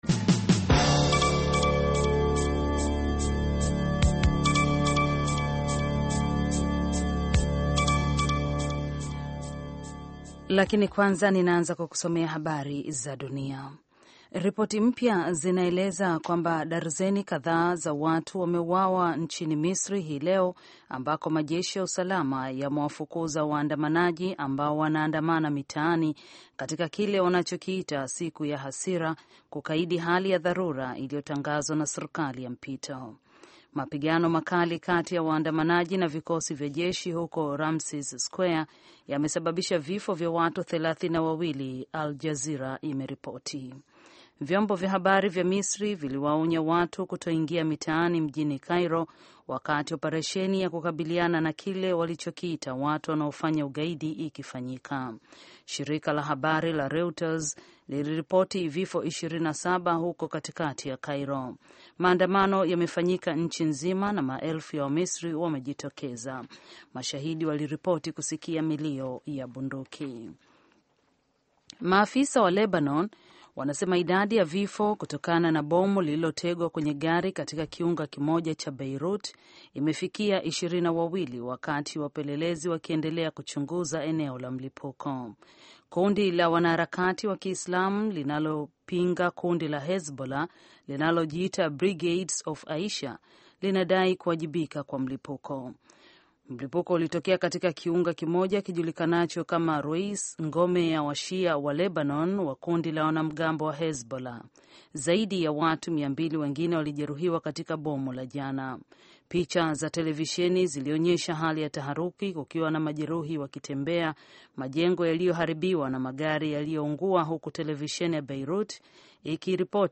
Taarifa ya Habari VOA Swahili - 6:23